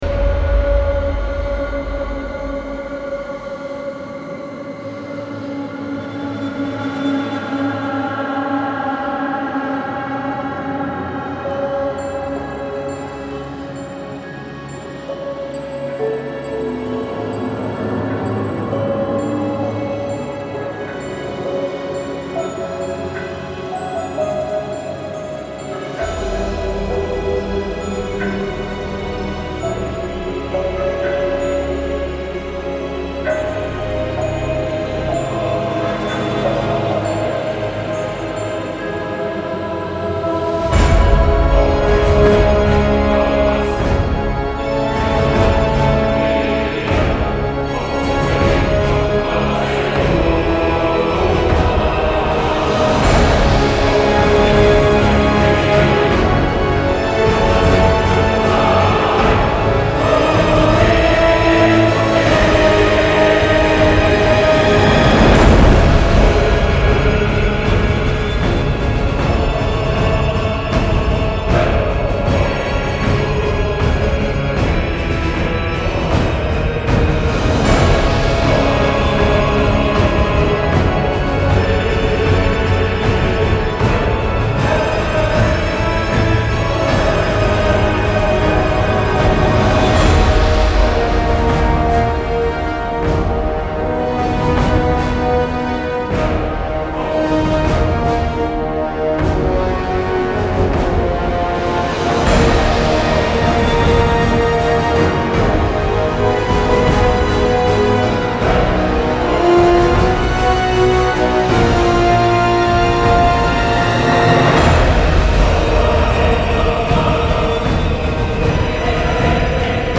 Genre : Pop, Rock, Metal